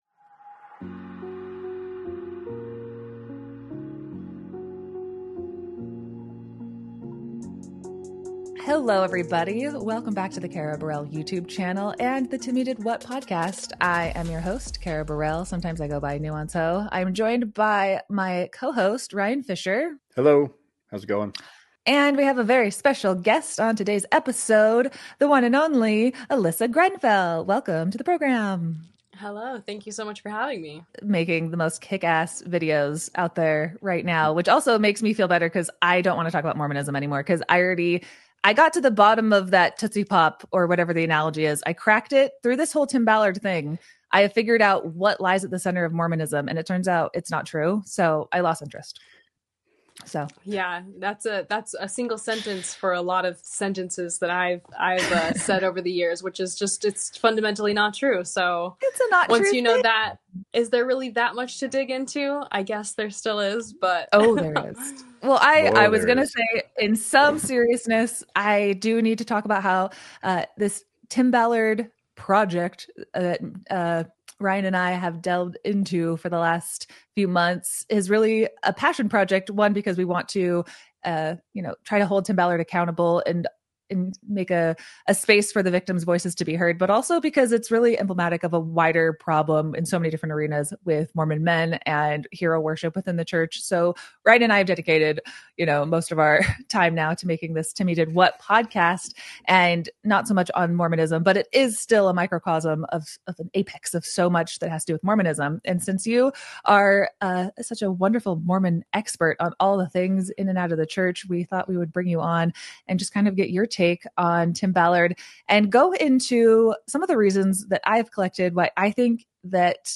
solo live stream